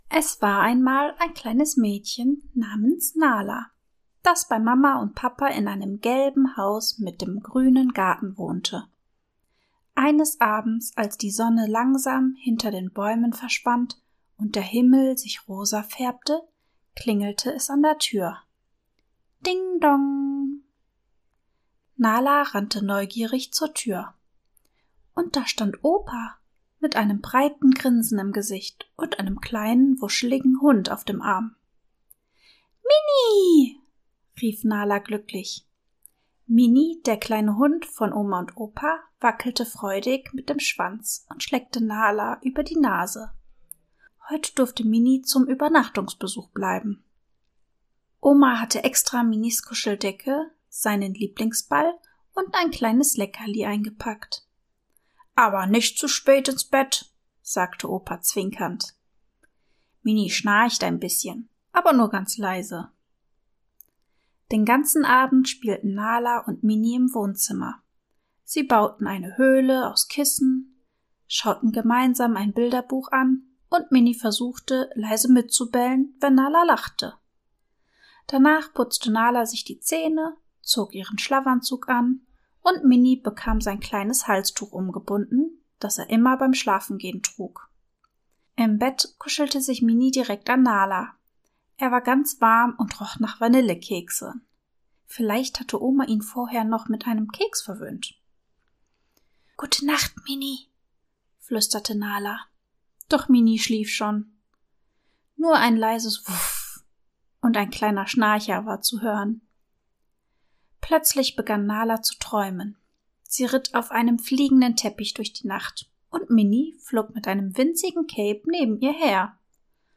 Eine warme, beruhigende Erzählung über Freundschaft,